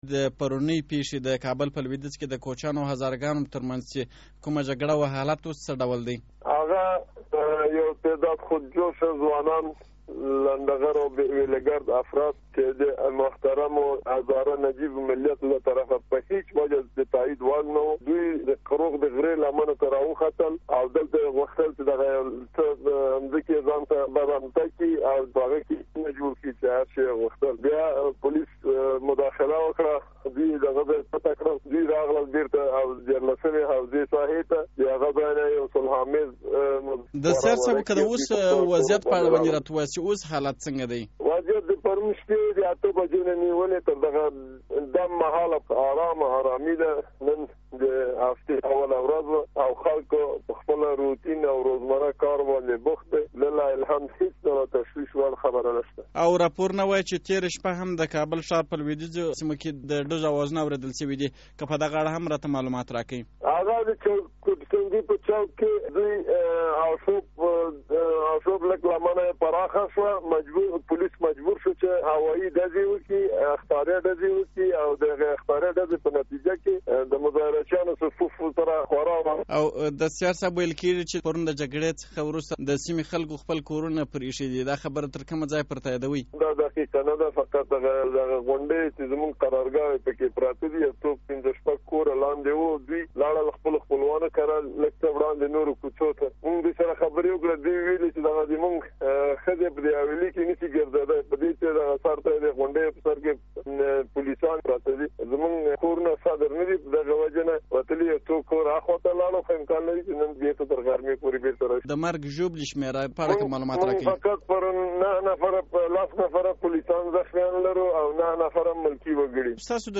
لنډه مرکه